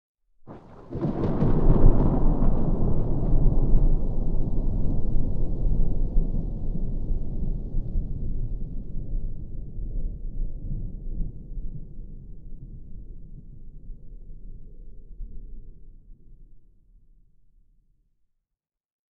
thunderfar_18.ogg